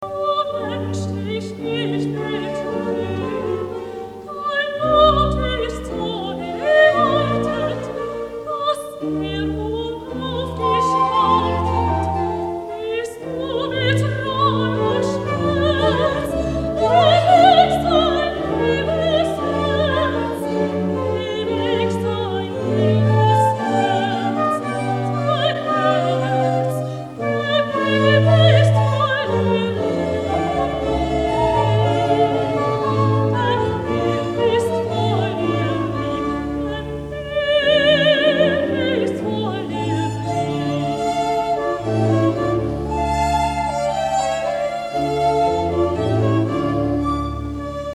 Oratorium